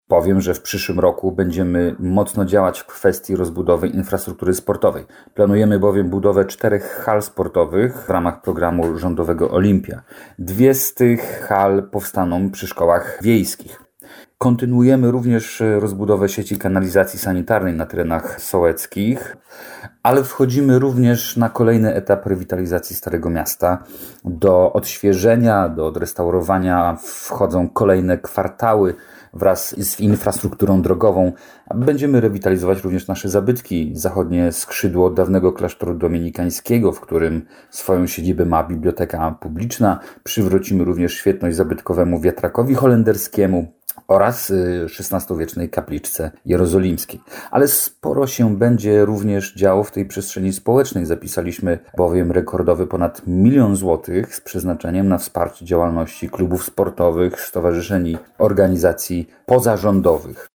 Burmistrz Piotr Sobolewski w rozmowie z Twoim Radiem przedstawił najważniejsze założenia wydatków inwestycyjnych.
MYSL-Sobolewski-inwestycje_2.mp3